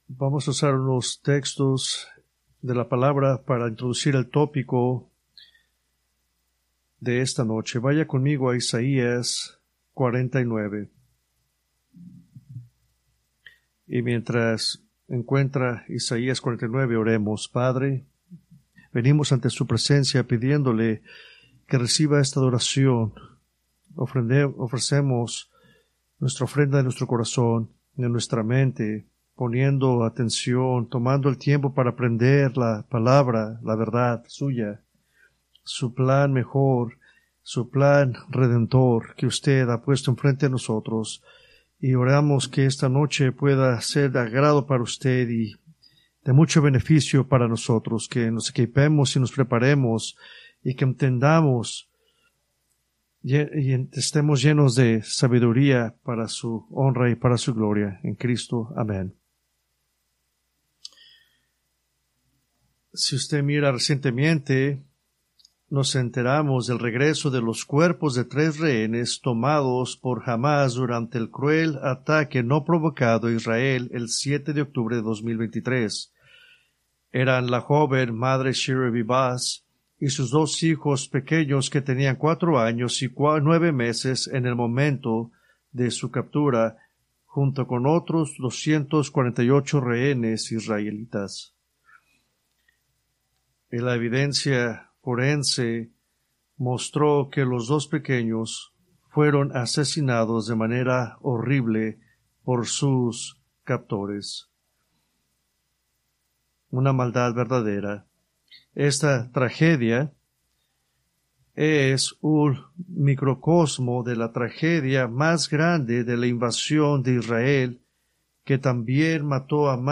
Preached March 2, 2025 from Escrituras seleccionadas